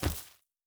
Tree Hit_1.wav